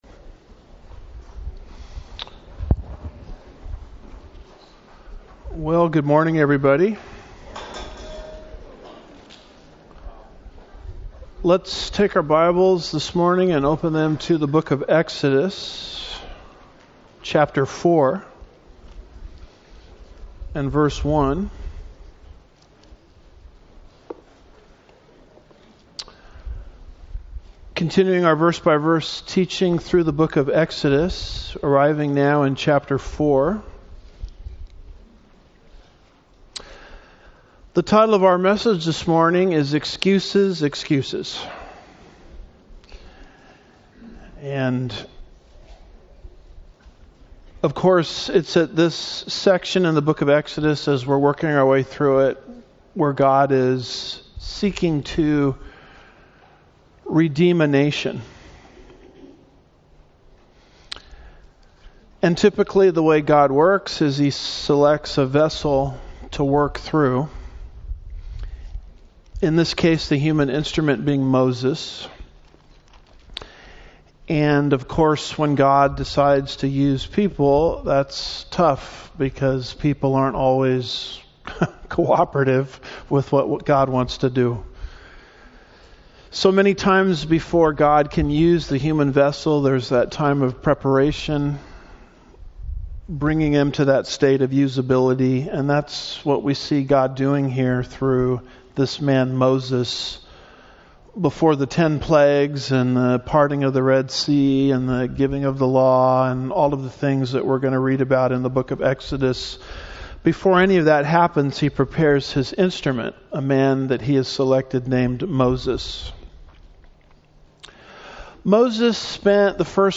Home / Sermons / Exodus 010 – Excuses, Excuses!